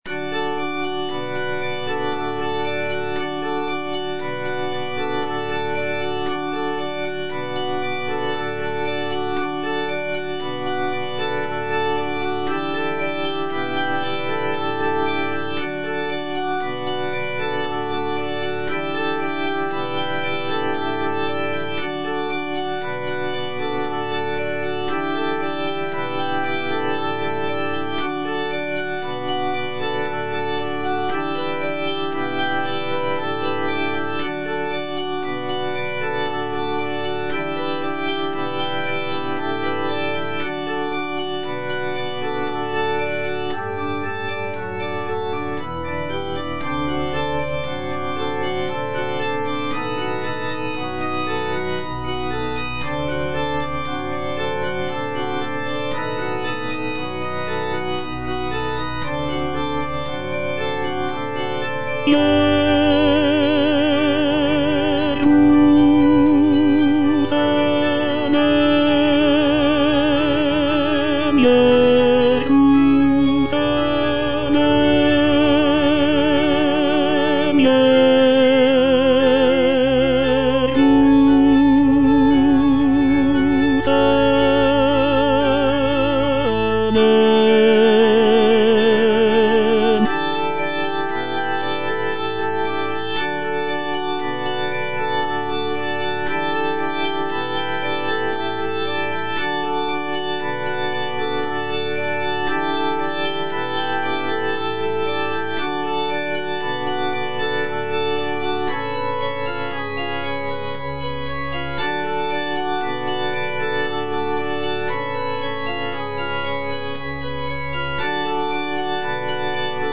G. FAURÉ - REQUIEM OP.48 (VERSION WITH A SMALLER ORCHESTRA) In paradisum (tenor I) (Voice with metronome) Ads stop: